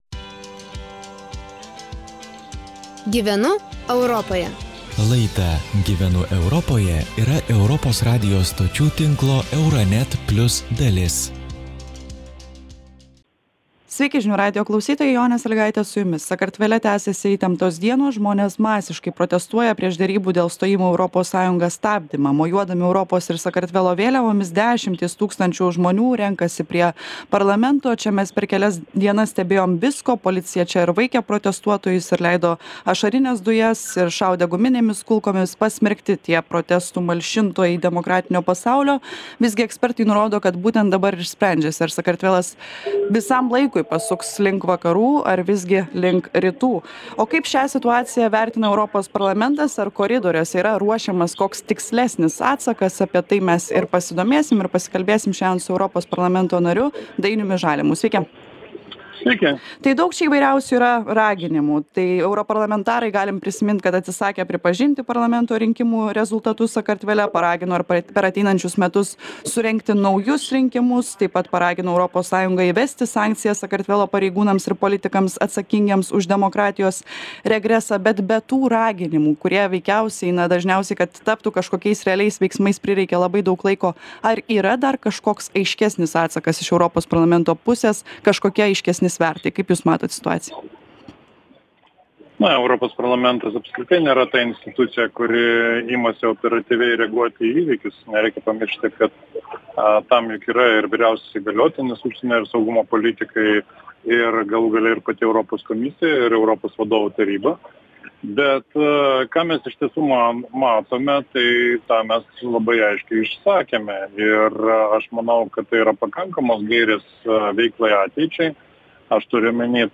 Pokalbis su Europos Parlamento nariu Dainiumi Žalimu.